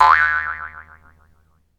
Add boing sound originally used for trampoline mod
sounds_boing.ogg